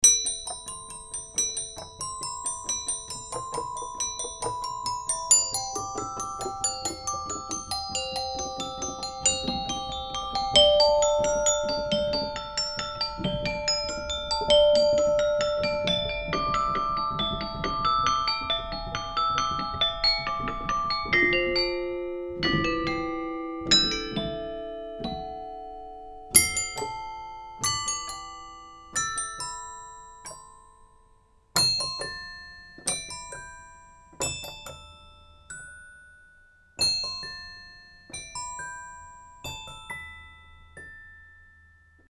Geht man der Reihe nach, so folgt dem Dulcitone das Toy Piano.
Auch hier wurde das Instrument mit einem Mono-, einem Stereo und einem Raummikrofon aufgenommen.
Im folgenden Audiodemo habe ich den Color Shift – Regler langsam von der Rechts- zur Linksposition und wieder zurück gedreht. Bei den letzten Noten habe ich die Transienten von Maximum auf Minimum reduziert.